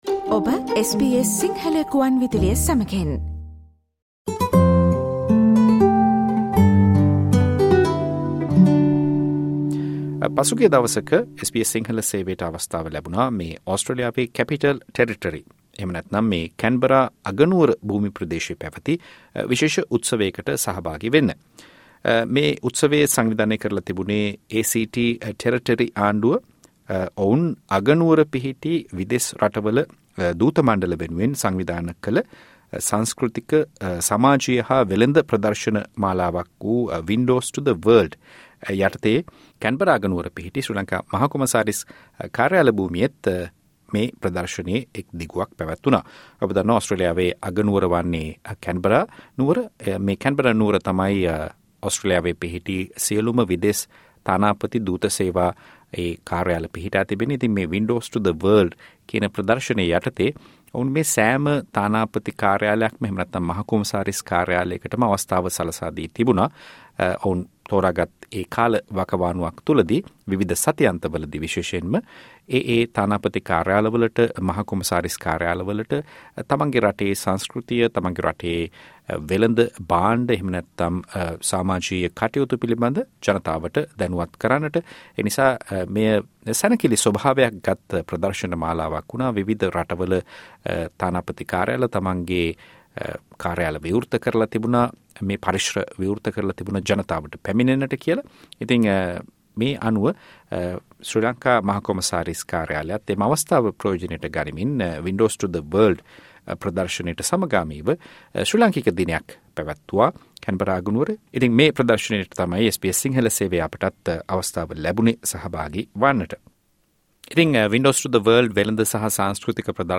ඕස්ට්‍රේලියාවේ කැන්බරා අගනුවර ප්‍රාන්ත රජය පසුගිය දා සංවිධාන කර තිබුණු Windows to the World ප්‍රදර්ශනය සඳහා ශ්‍රී ලංකා මහ කොමසාරිස් කාර්යාලයත් එක්ව තිබුණා. මේ එම ප්‍රදර්ශනයට සහභාගි වූ ශ්‍රී ලාංකික ව්‍යවසායකයින් දෙදෙනෙක් SBS සිංහල සේවයට දැක්වූ අදහස්.